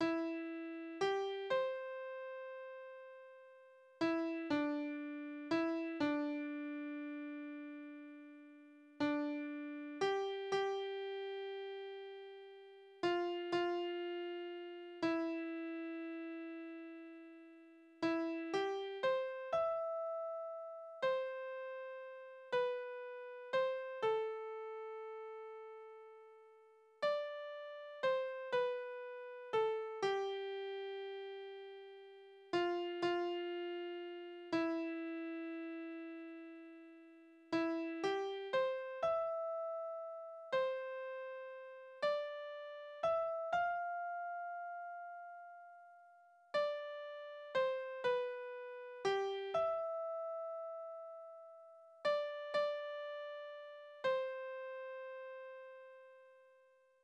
Balladen: Die Blume Männertreu
Tonart: C-Dur
Taktart: 3/4
Tonumfang: kleine None
Besetzung: vokal